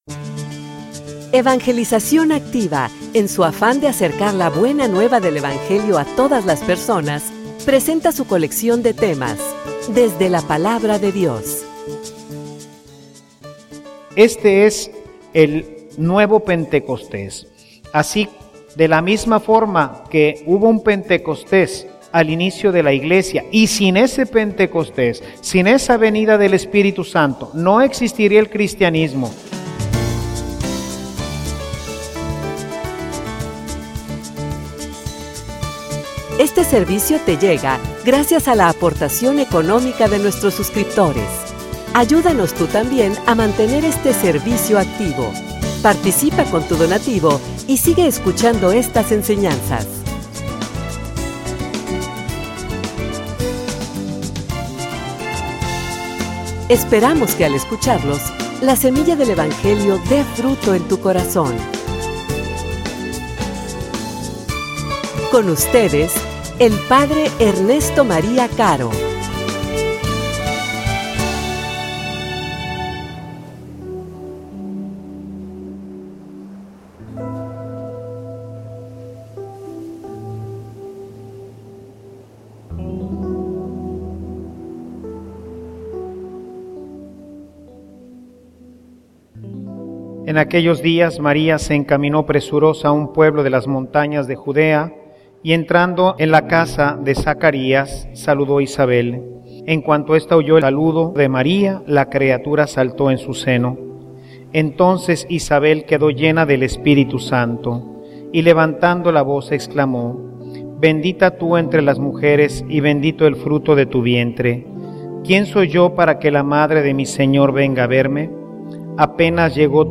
homilia_Pentecostes_Guadalupano.mp3